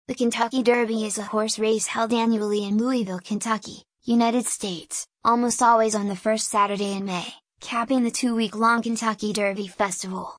Voice Text